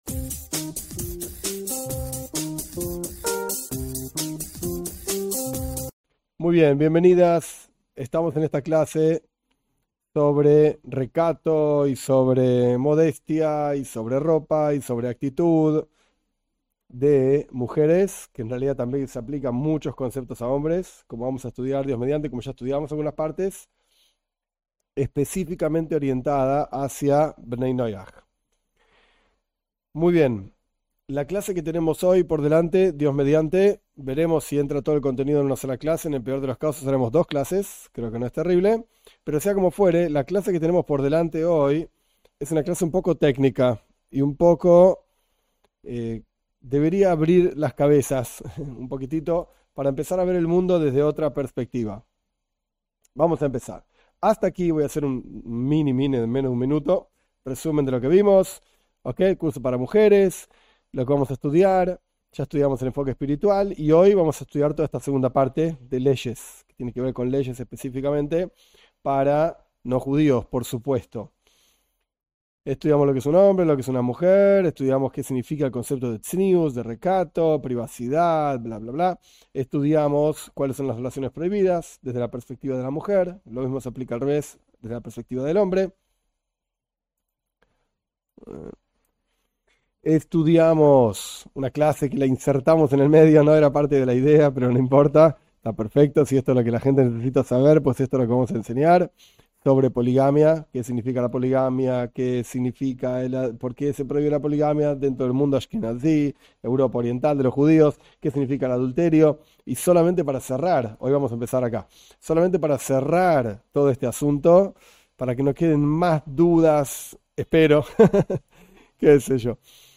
Esta es una serie de clases para mujeres sobre las ideas y leyes de recato / modestia / Tzinut para mujeres, con un enfoque especial en Bnei Noaj.